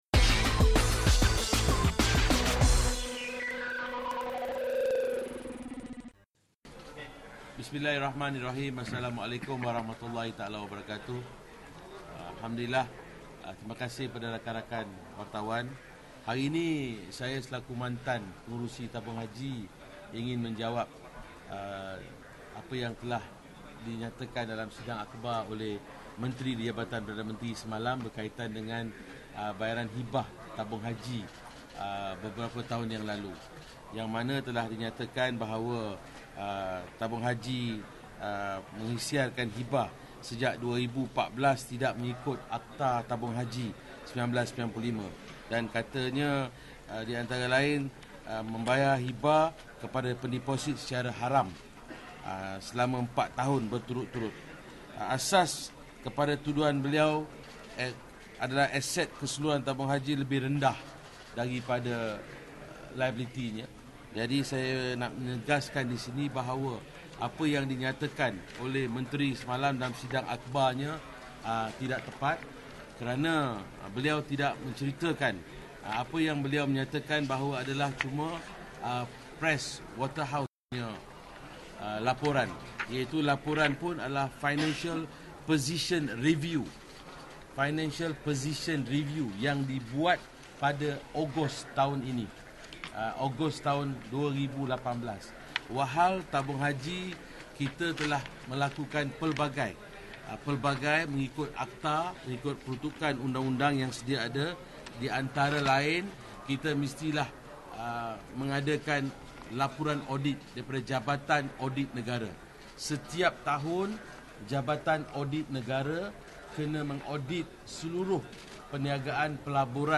Berikut adalah sidang media oleh Ahli Parlimen Baling, Datuk Seri Abdul Azeez bin Abdul Rahim berkenaan dengan 'isu Hibah Tabung Haji'.